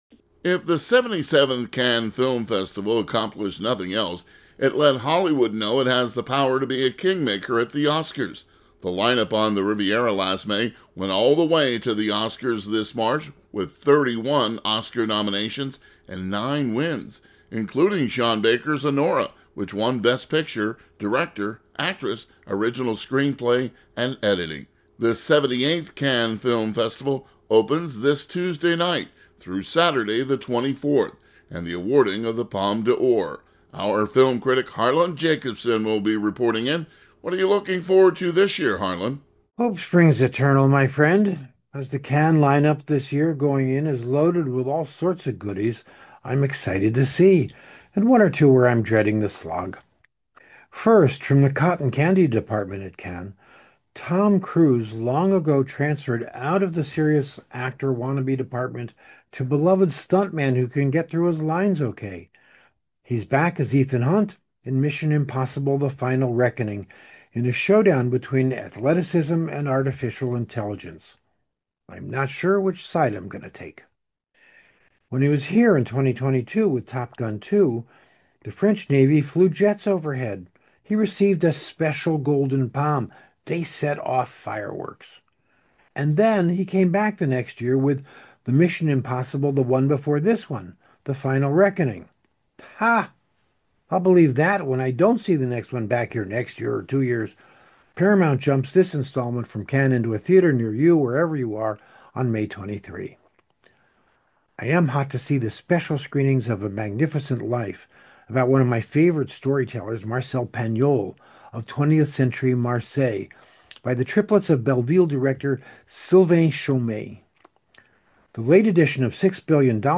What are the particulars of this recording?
Broadcast on WBGO, May 10, 2025